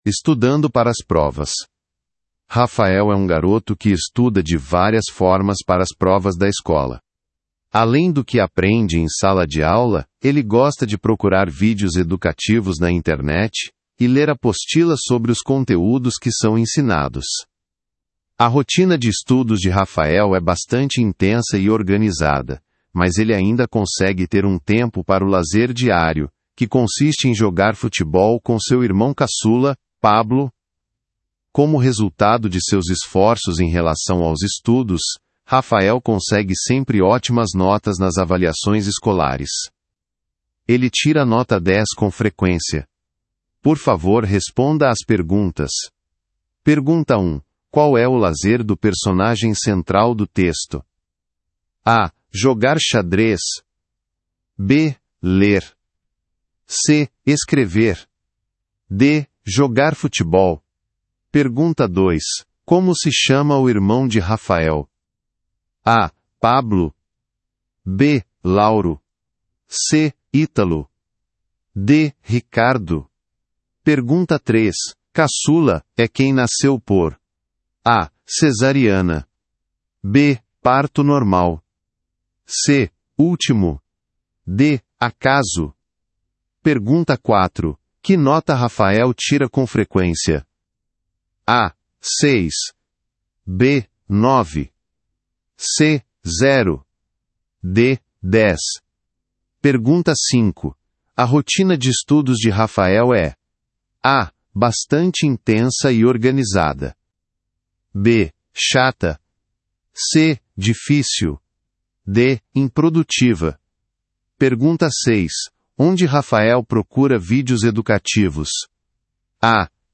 Brasil